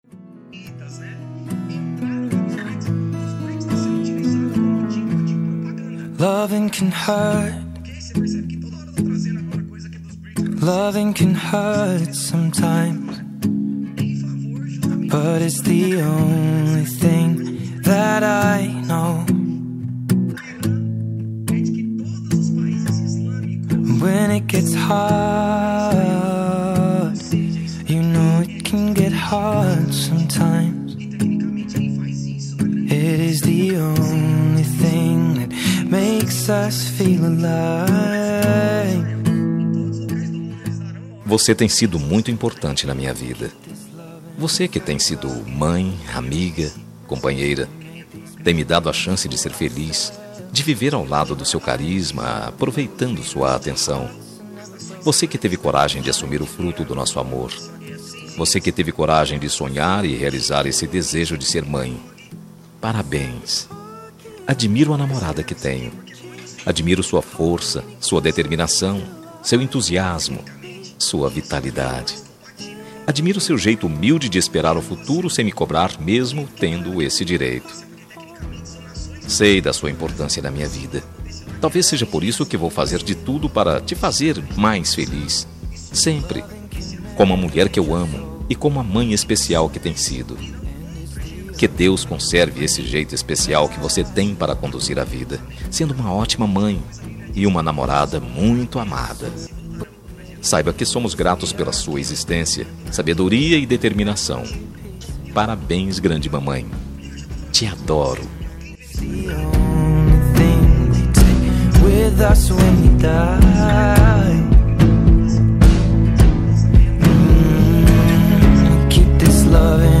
Dia das Mães – Para Namorada – Voz Masculina – Cód: 6513